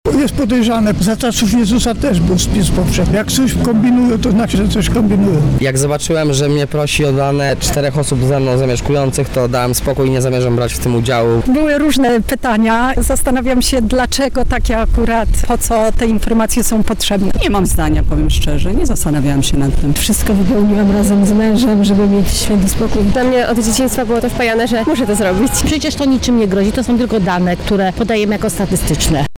Co mieszkańcy Lublina myślą o Narodowym Spisie Powszechnym?